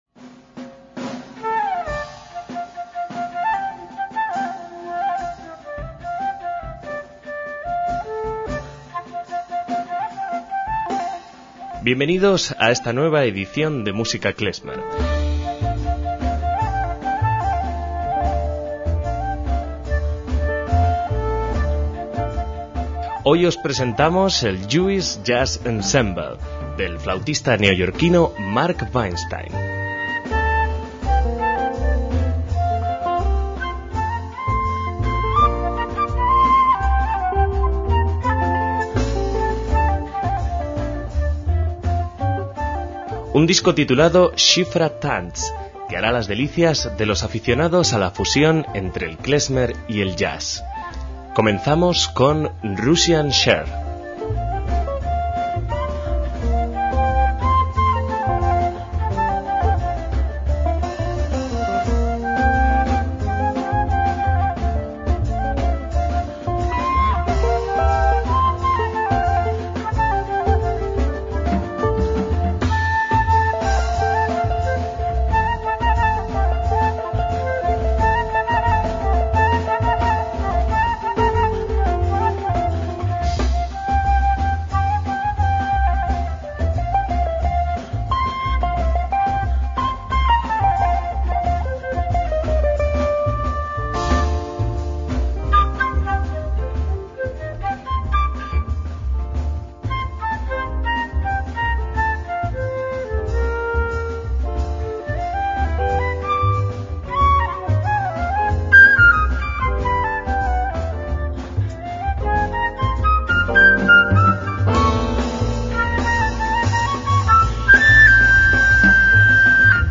MÚSICA KLEZMER
guitarra
contrabajo
percusión